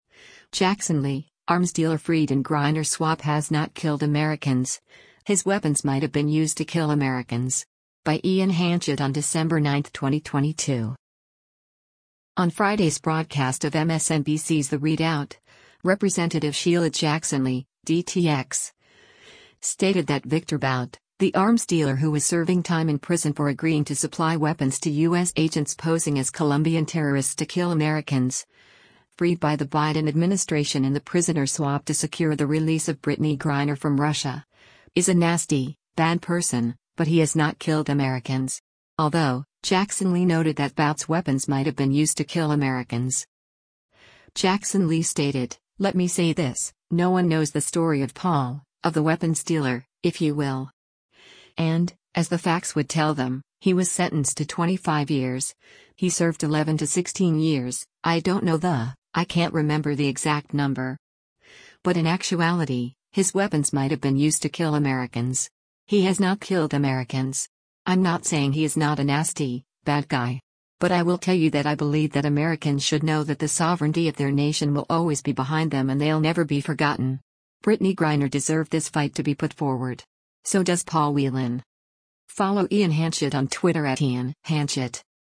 On Friday’s broadcast of MSNBC’s “The ReidOut,” Rep. Sheila Jackson Lee (D-TX) stated that Viktor Bout, the arms dealer who was serving time in prison for agreeing to supply weapons to U.S. agents posing as Colombian terrorists to kill Americans, freed by the Biden administration in the prisoner swap to secure the release of Brittney Griner from Russia, is “a nasty, bad” person, but “He has not killed Americans.”